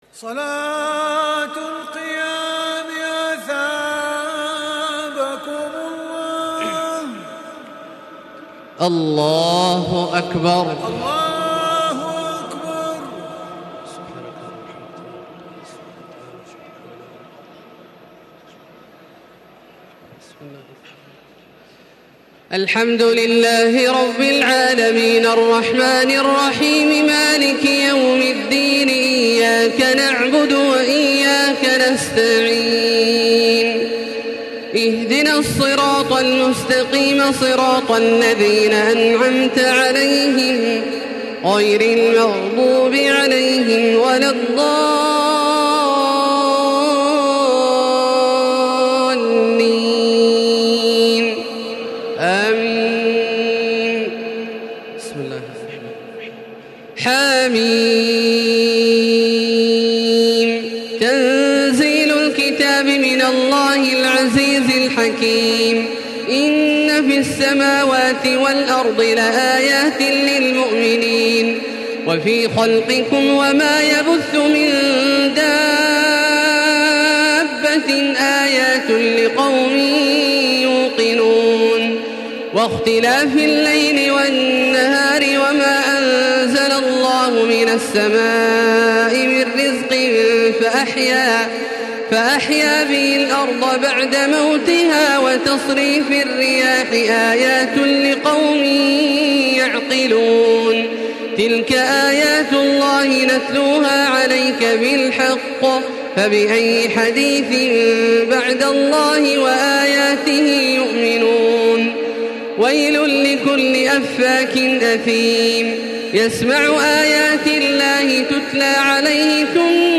تراويح ليلة 25 رمضان 1435هـ من سور الجاثية و الأحقاف و محمد Taraweeh 25 st night Ramadan 1435H from Surah Al-Jaathiya and Al-Ahqaf and Muhammad > تراويح الحرم المكي عام 1435 🕋 > التراويح - تلاوات الحرمين